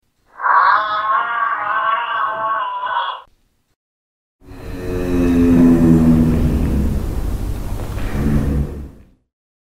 GIRAFFE
Giraffe.mp3